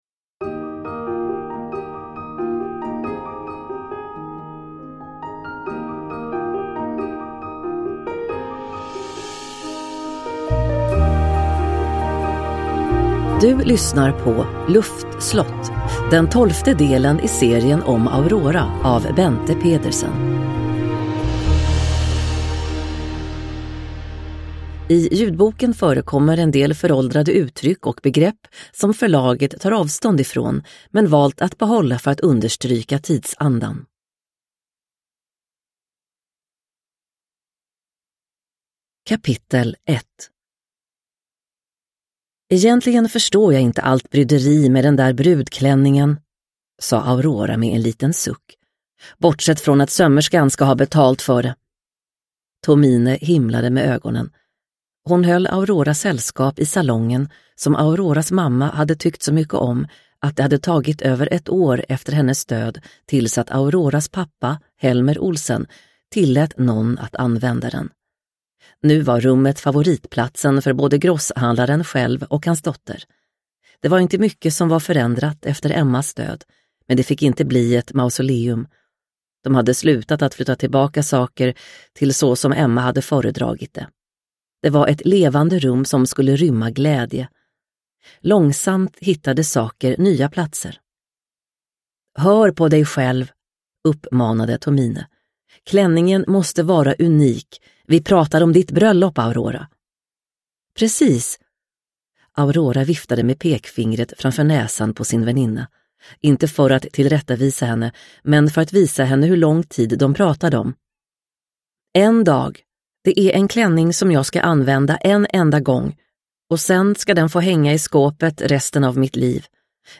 Luftslott – Ljudbok – Laddas ner